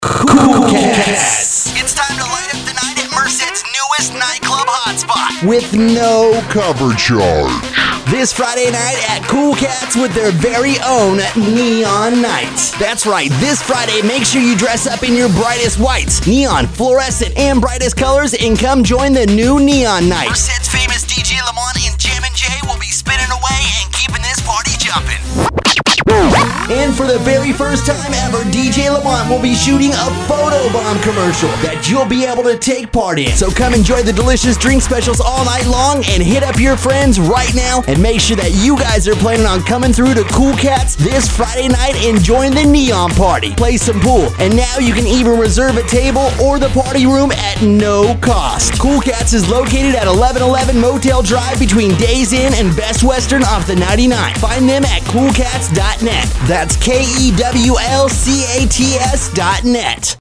Check out the new Kewl Cats radio spot on HOT 104.7 FM and KABX 97.5 FM.